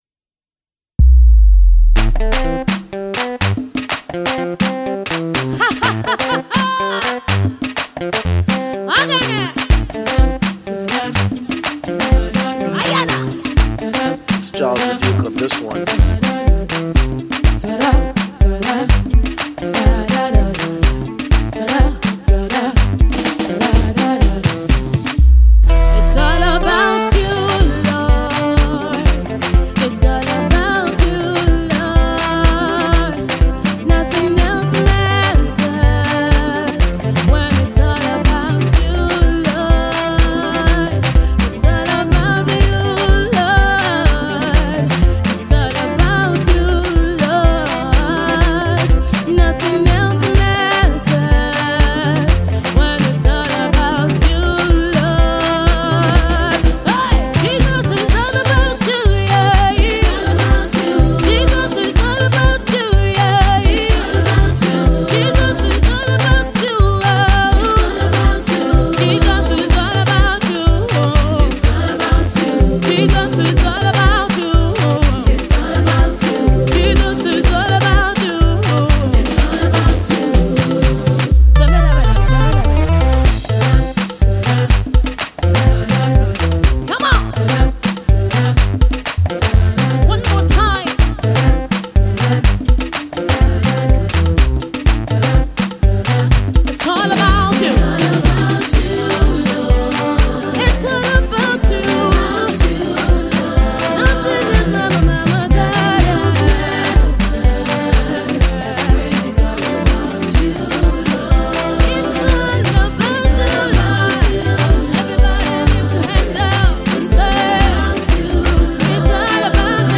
upbeat
single